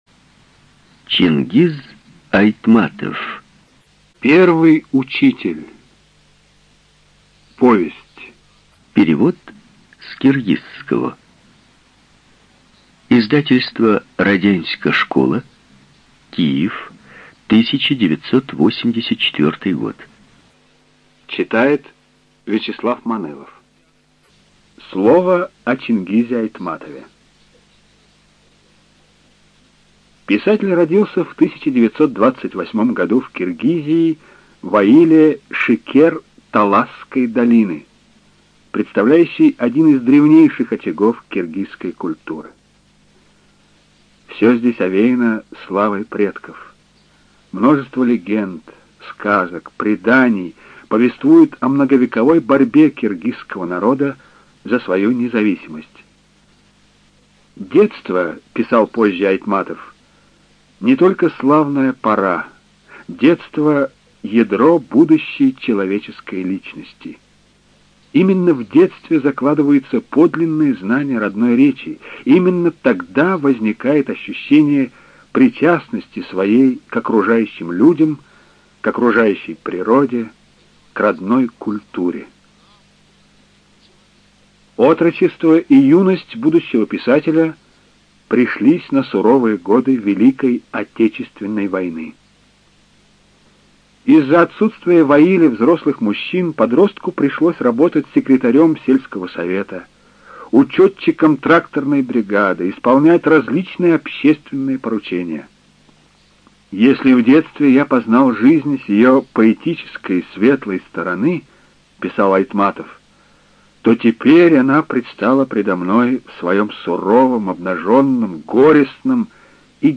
Студия звукозаписиРеспубликанский дом звукозаписи и печати УТОС